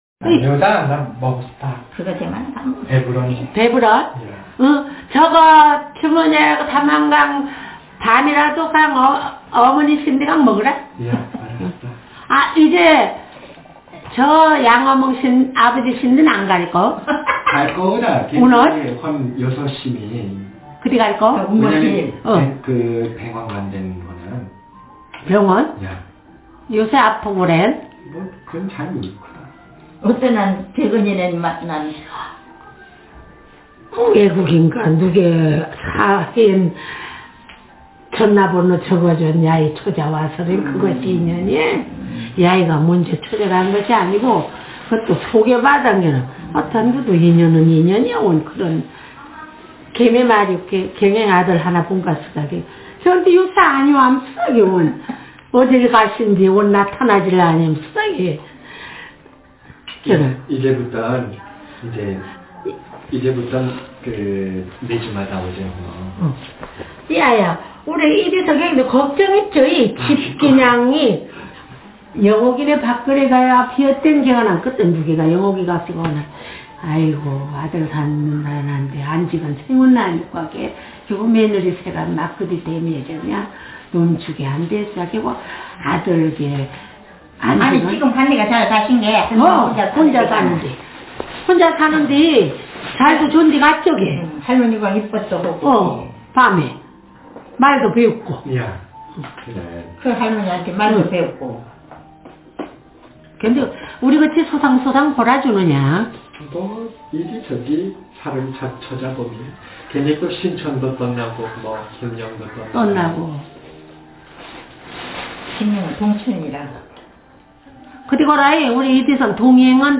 Speaker sex f Text genre conversation